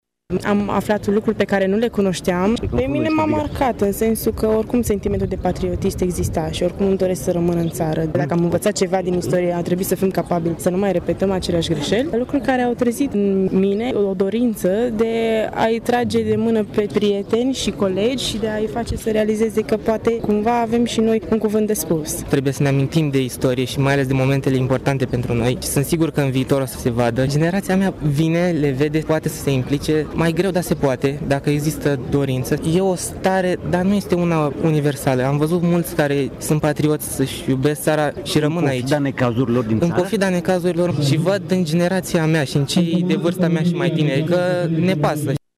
A fost prezent, pentru prima dată, un pluton al studenților la medicină militară de la UMF Tg. Mureș: